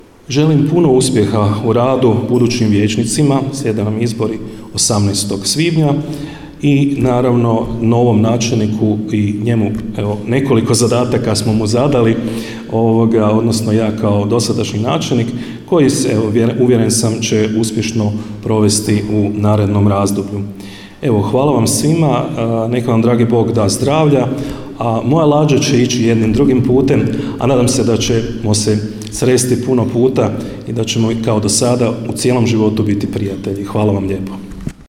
Obilježba je zaključena prigodnim programom u Društvenom domu i turističko-informativnom centru u Lekeniku, gdje su načelnik Ivica Perović i sada već bivši predsjednik Općinskog vijeća Marin Čačić dodijelili javna priznanja Općine Lekenik u 2025. godini.